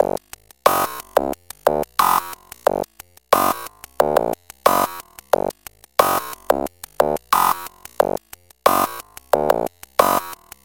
突击鼓循环
描述：90bpm, Glitch Hop鼓循环。